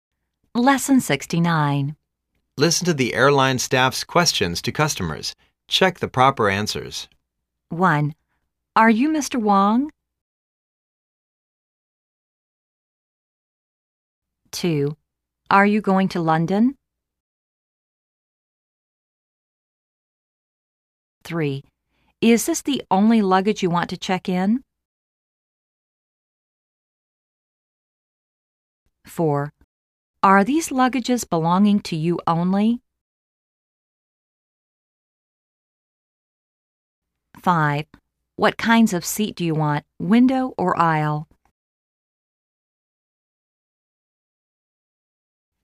Listen to the airline staffs' questions to customers and check the proper answers.